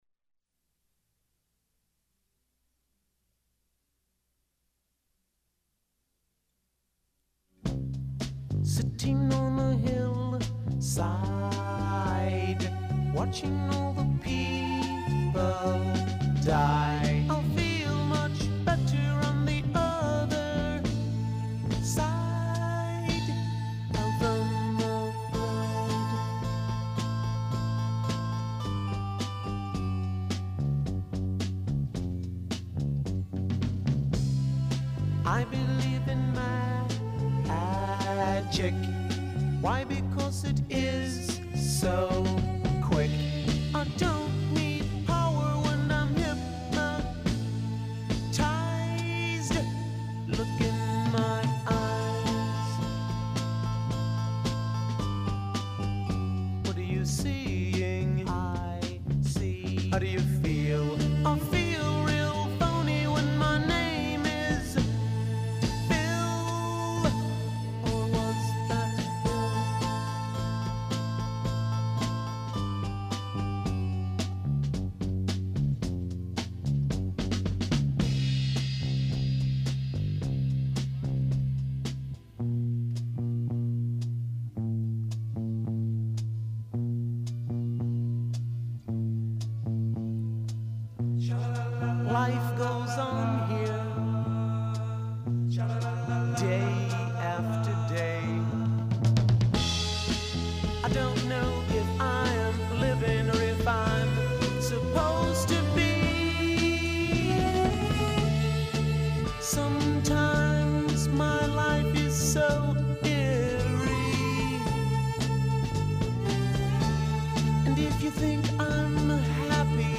let ska & rock steady please
Un peu de reggae